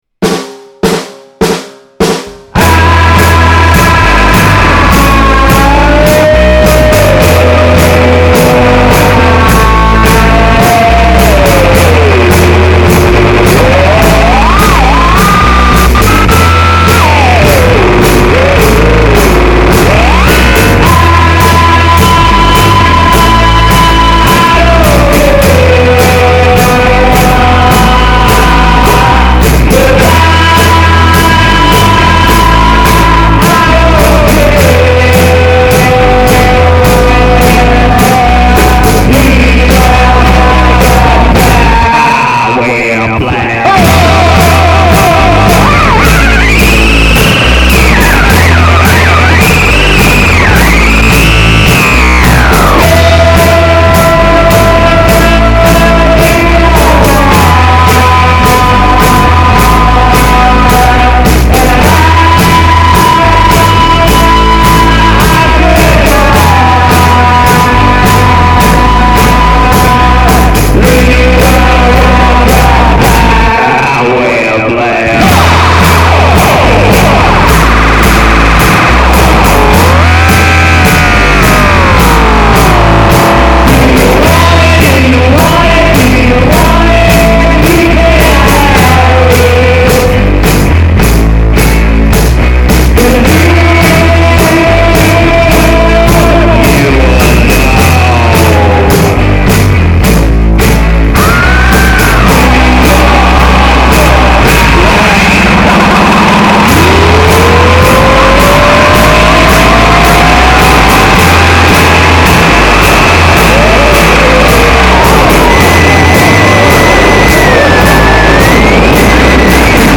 baritone and alto vocals layer pretty well